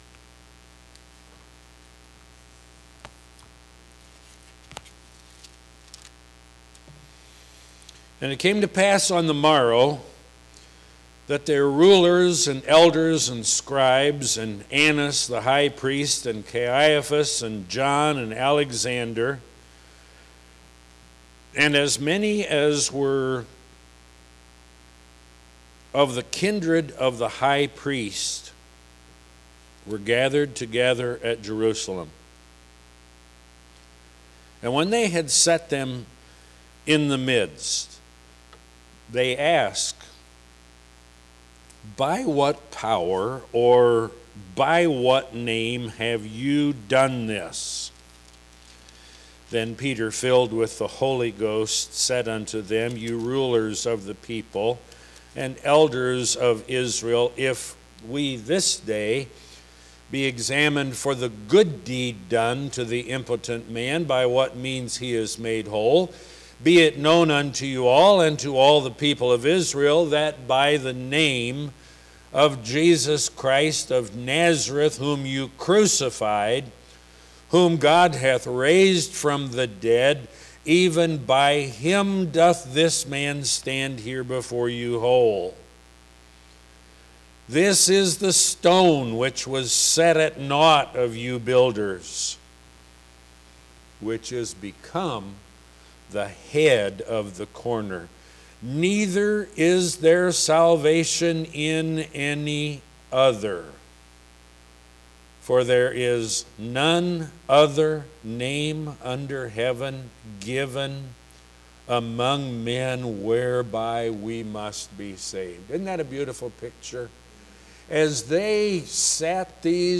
Guest Speaker
Scripture - Daniel 9:20-27 When - Sunday Evening Service